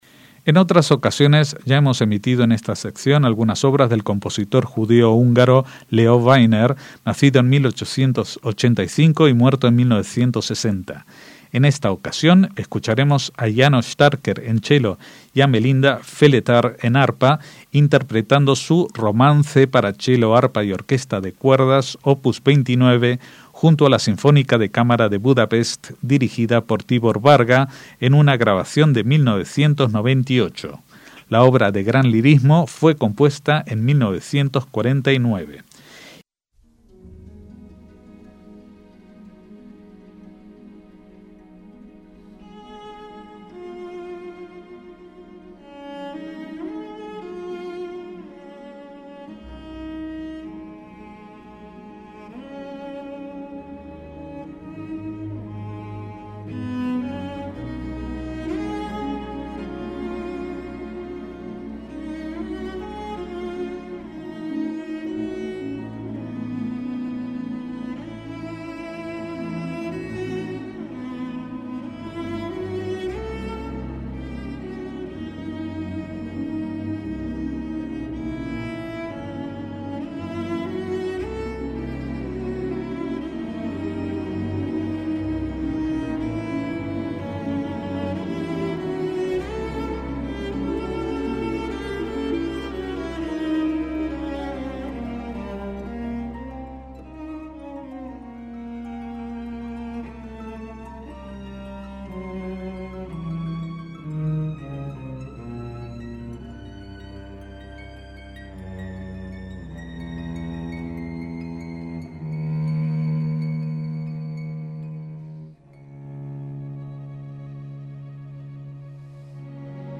MÚSICA CLÁSICA
para chelo, arpa y orquesta de cuerdas